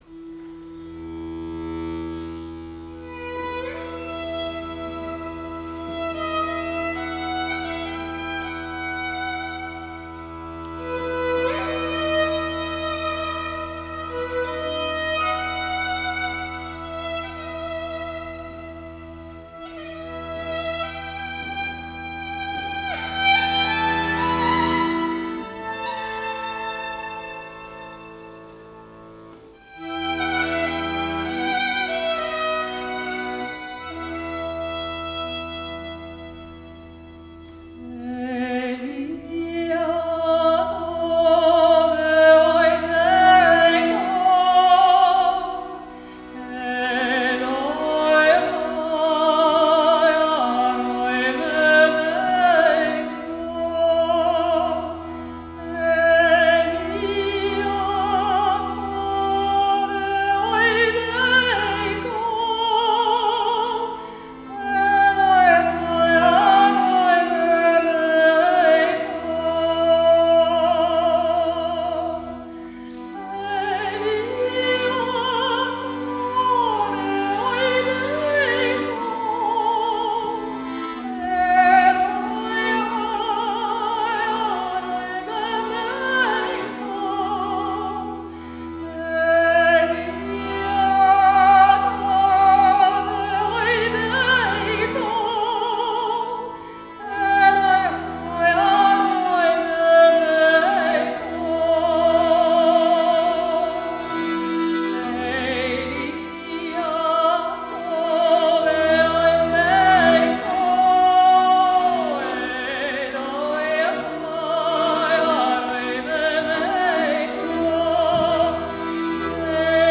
violon
accordéon à boutons
clarinette, clarinette basse
chant, guitare